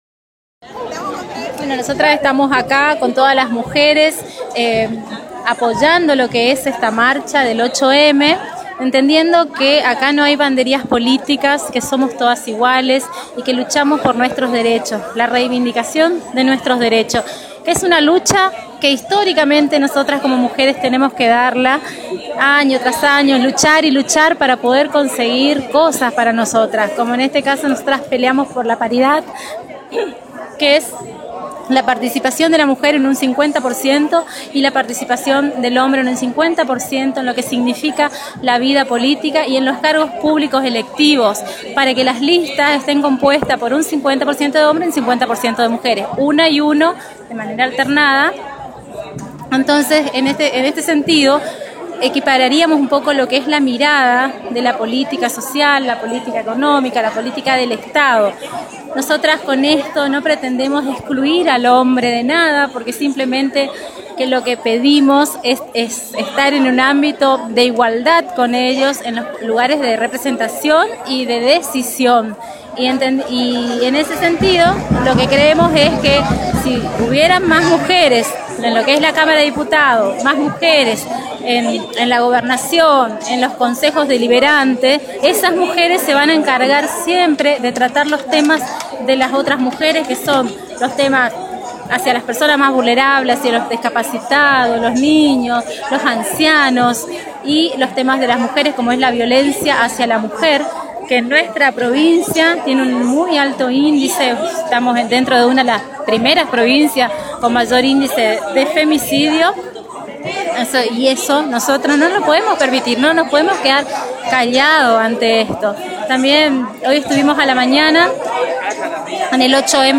Silvia-Rojas-8M-Plaza.mp3